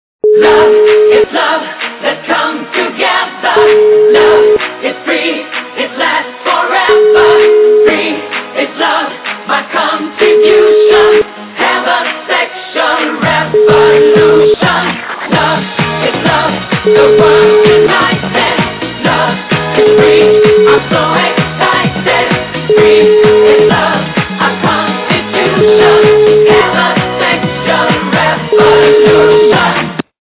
При заказе вы получаете реалтон без искажений.